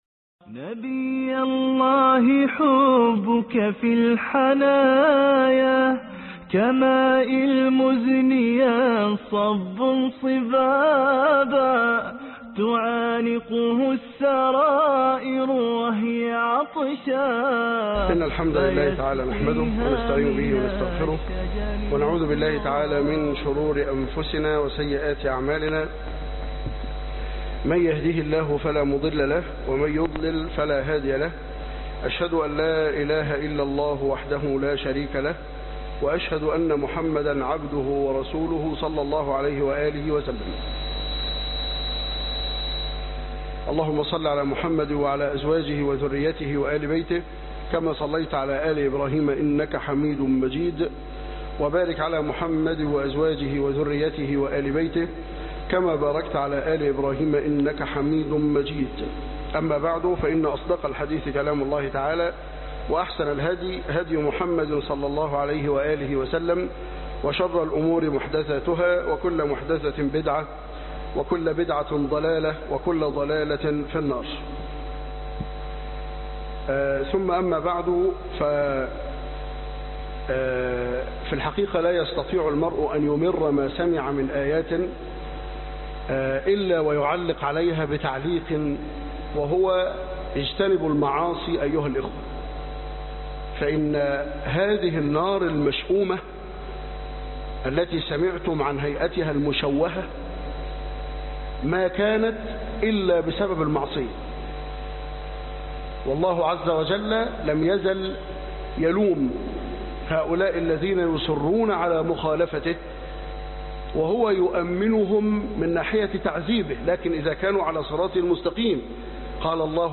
الدرس 14